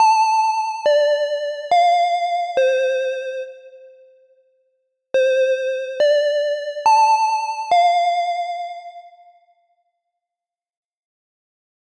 Scary School Bell
Alarm Antique Bell Car Close Dial Ding Door sound effect free sound royalty free Sound Effects